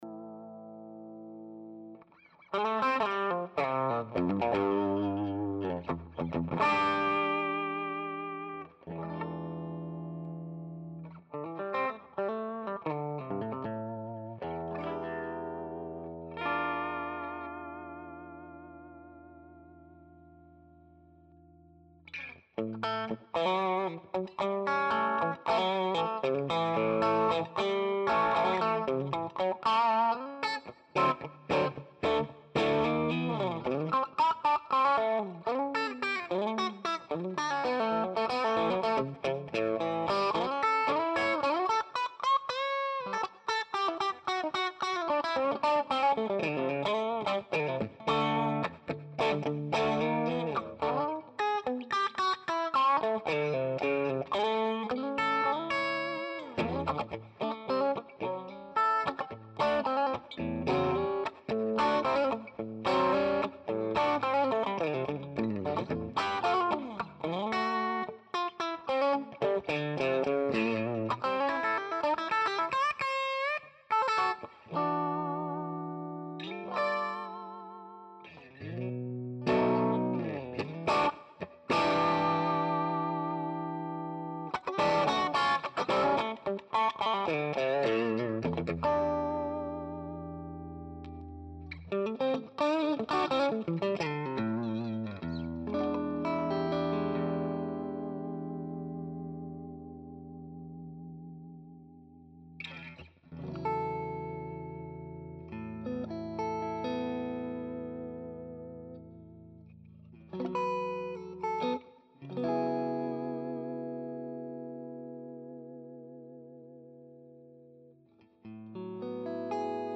One dry and one with fx.
Diddy1 - FX
It's interesting so see how easily you can go from pickin clean to OD on that clip.
This one was done with the controls all at 12 o'clock except for the master that was at 3 o'clock. The guitar was plugged into the bright channel and the overdrive was engaged.